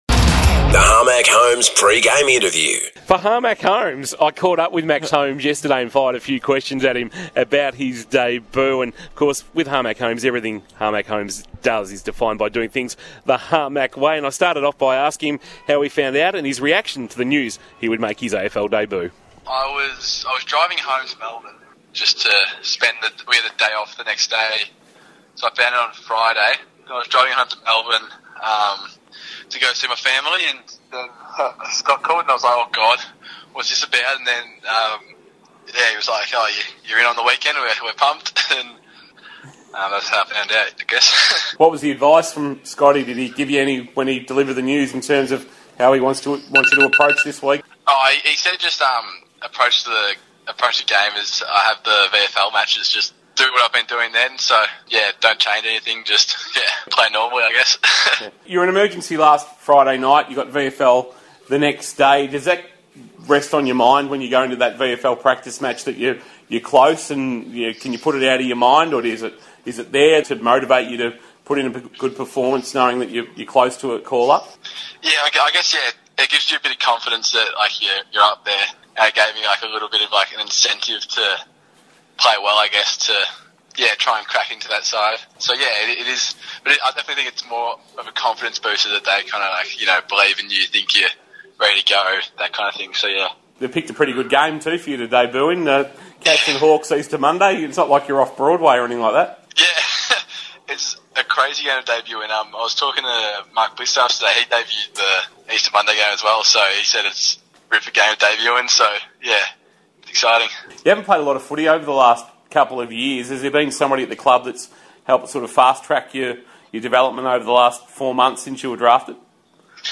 PRE-MATCH INTERVIEW: MAX HOLMES - Geelong Cats